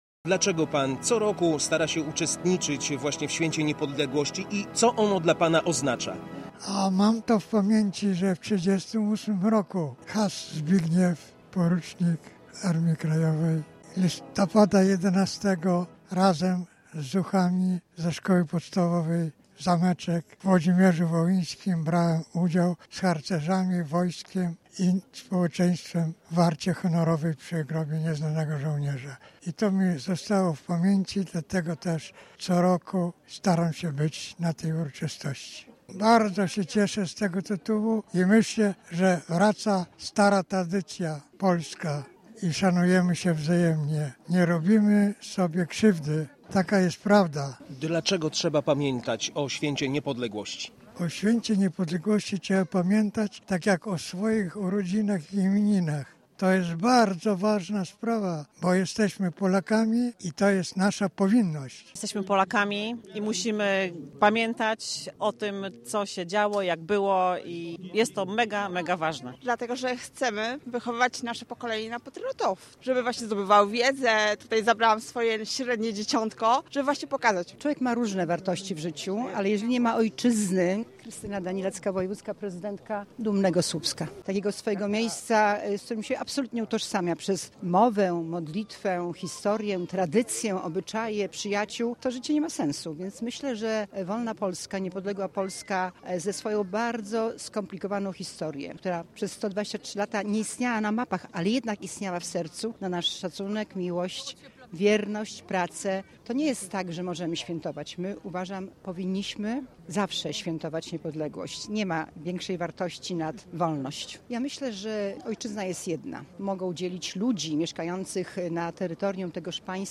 Na początku się modlili, a na końcu strzelali z broni. Obchody Święta Niepodległości w Słupsku
Odśpiewano także hymn narodowy.